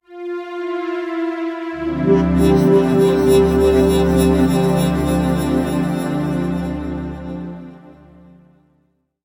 SFX遗憾悲伤的影视配音音效下载
SFX音效